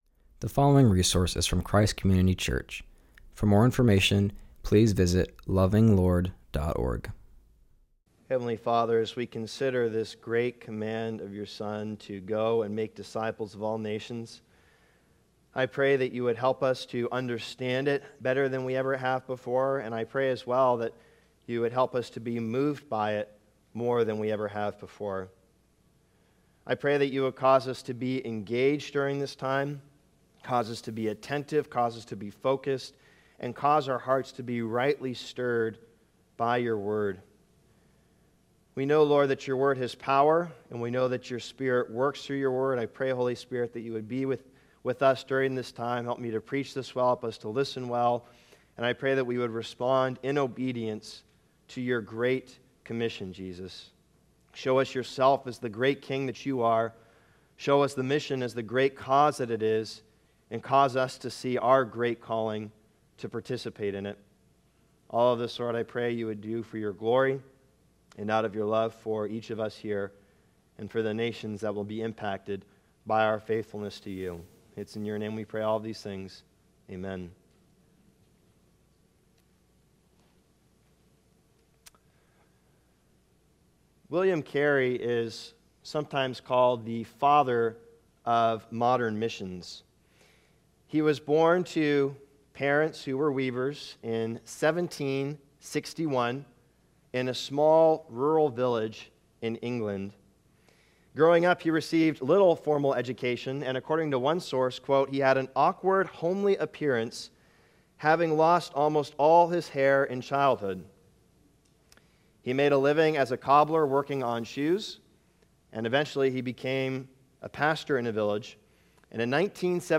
preaches from Matthew 28:16-20.